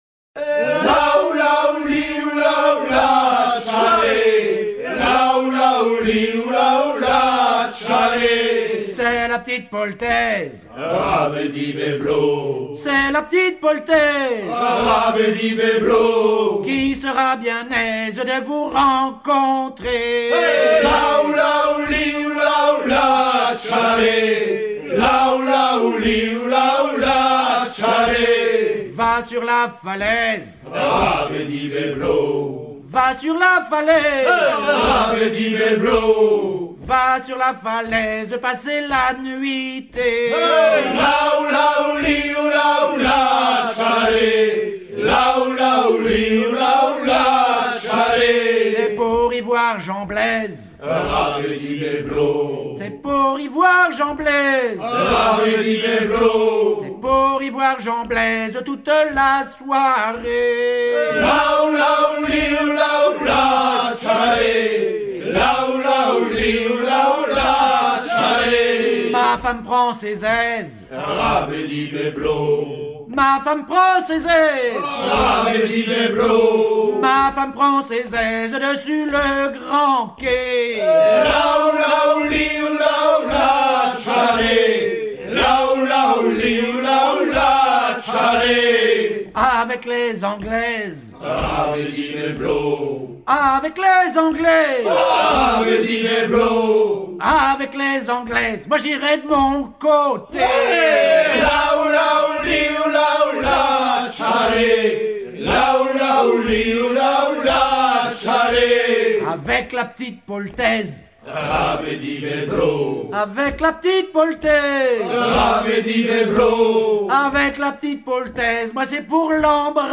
Fonction d'après l'analyste gestuel : à haler
Usage d'après l'analyste circonstance : maritimes
Genre laisse
Pièce musicale éditée